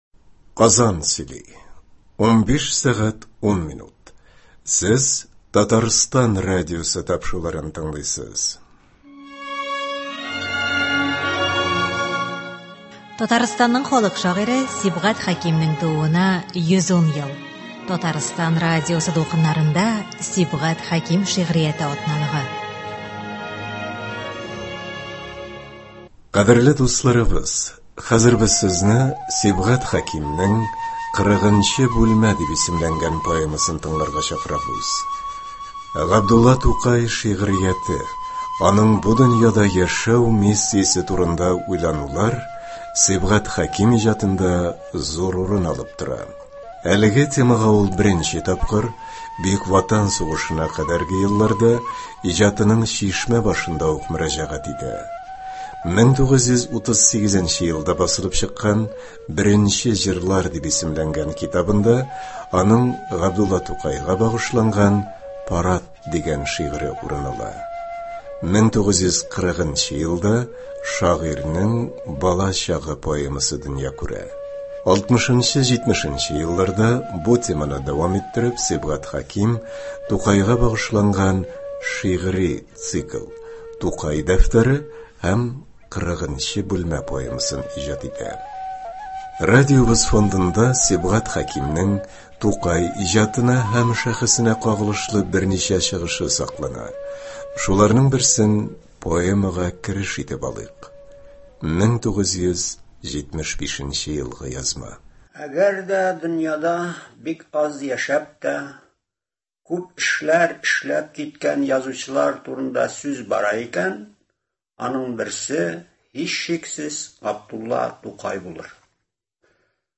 Радиокомпозиция (04.12.21)